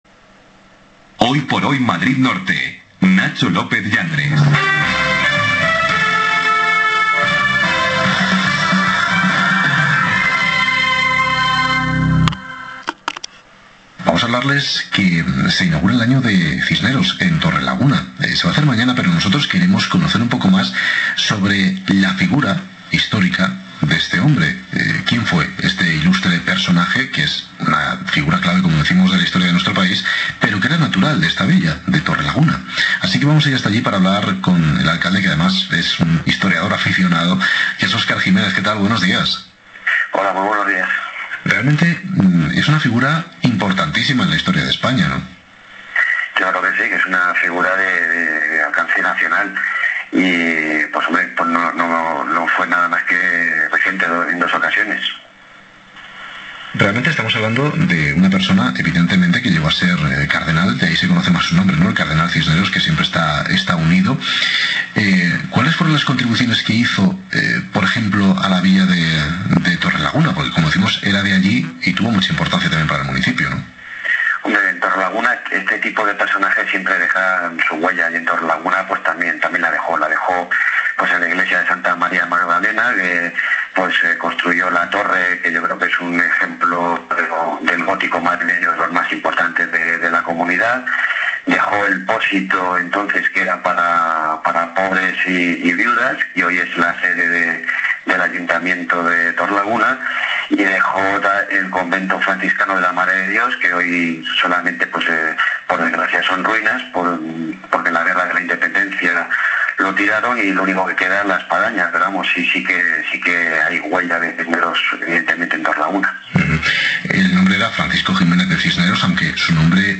Entrevista a nuestro alcalde en Ser Madrid Norte
Hoy por Hoy Madrid Norte, de Ser Madrid Norte, ha entrevistado esta mañana a nuestro alcalde, Óscar Jiménez Bajo, con motivo del V Centenario de la muerte del Cardenal Cisneros.